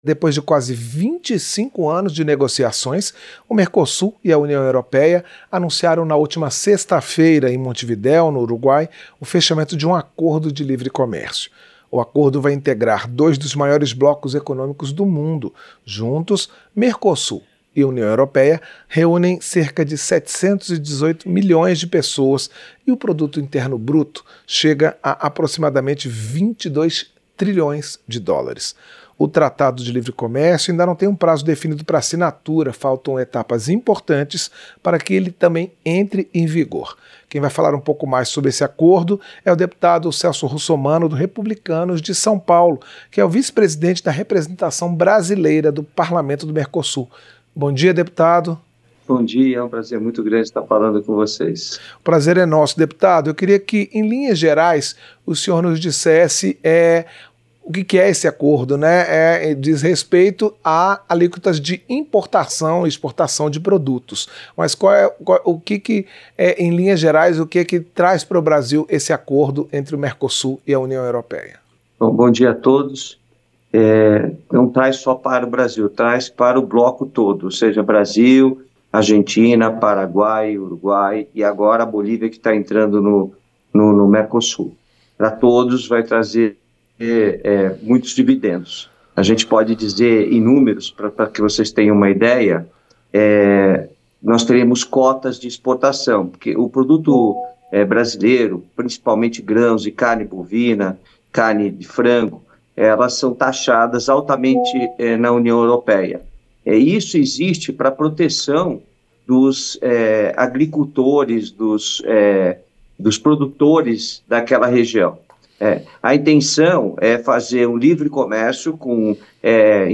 Entrevista - Dep. Celso Russomanno (Republicanos-SP)